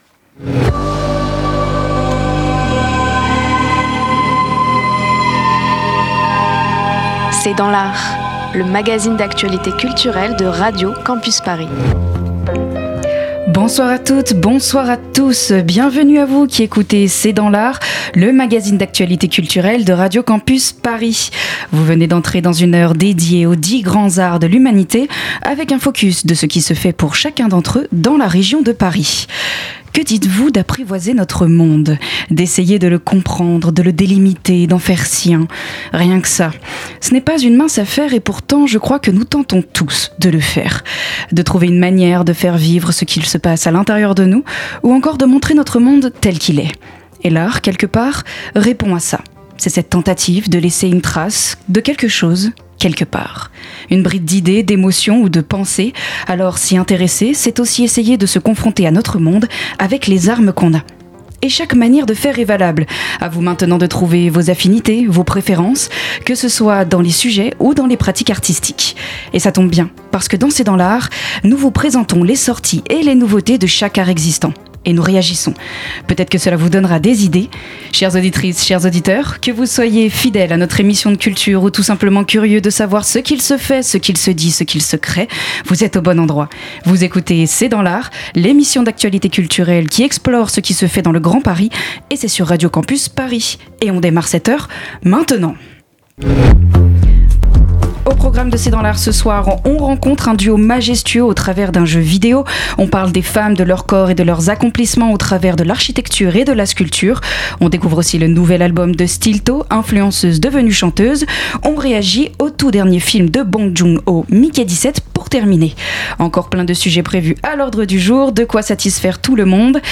C'est dans l'art, c'est le magazine d'actualité culturelle de Radio Campus Paris